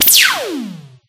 Laser1.ogg